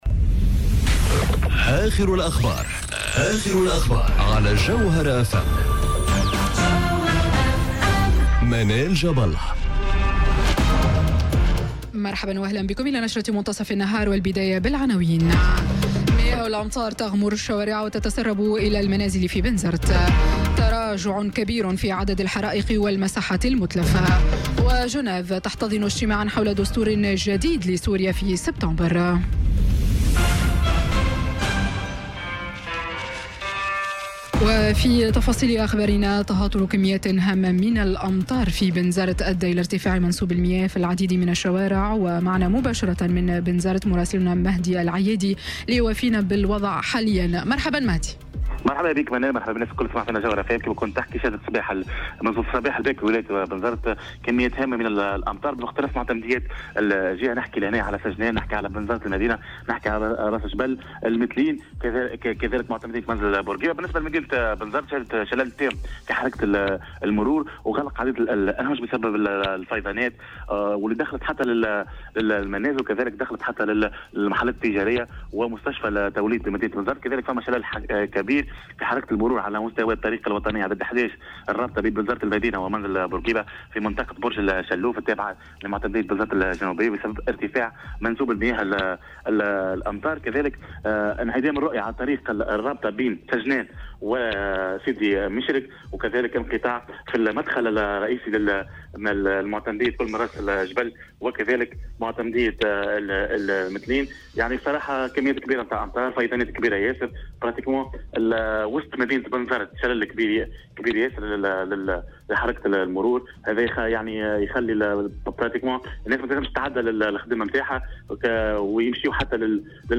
نشرة أخبار منتصف النهار ليوم الجمعة 24 أوت 2018